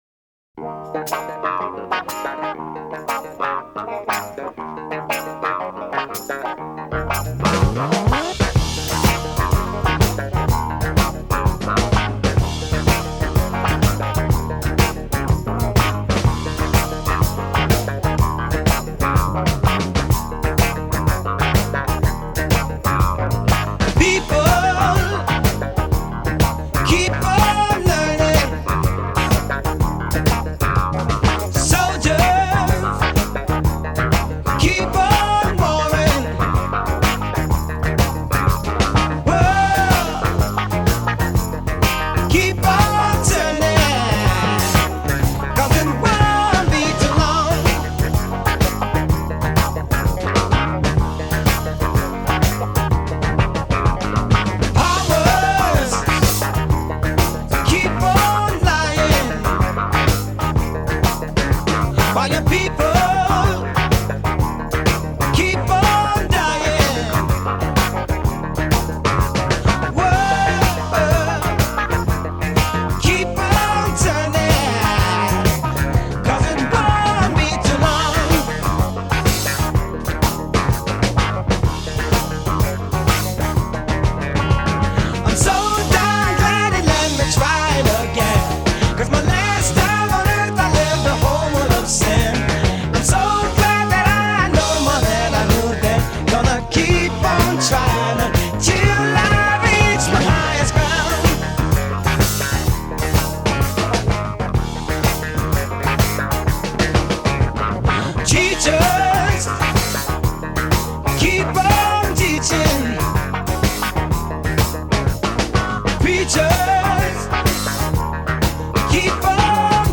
key = Eb